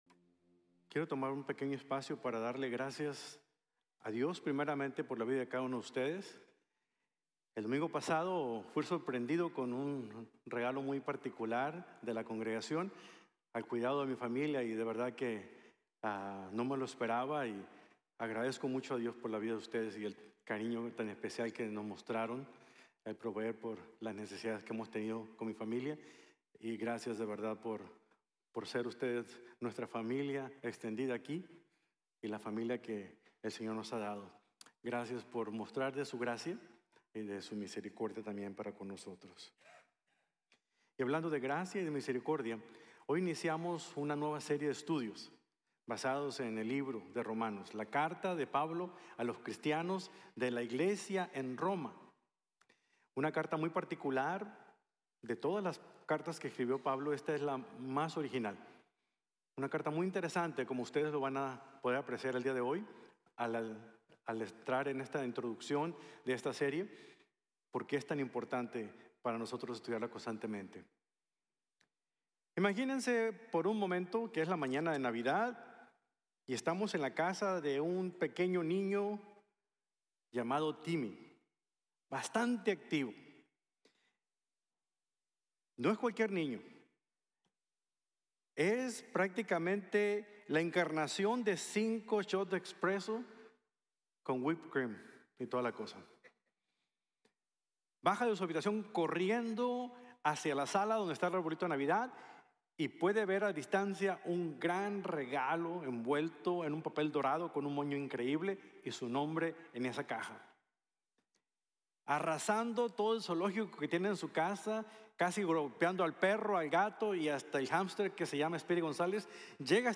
Las mejores noticias | Sermon | Grace Bible Church